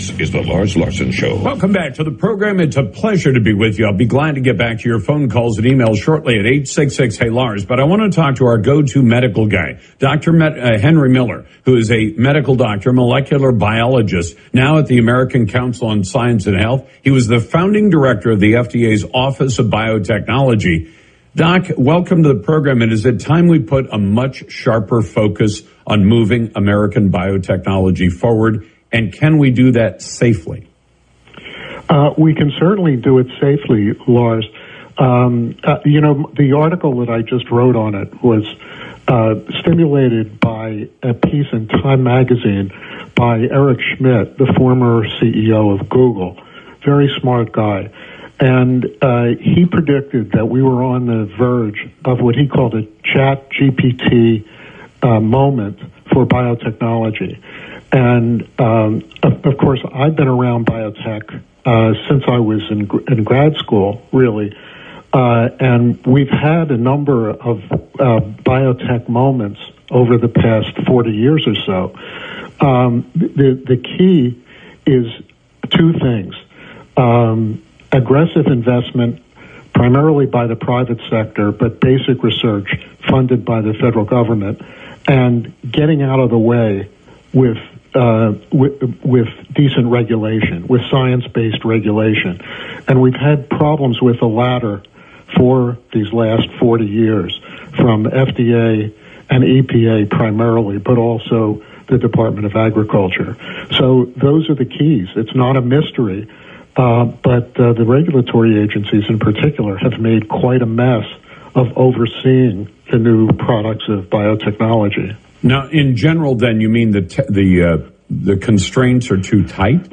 Lars Larson and I discuss the future of American biotechnology.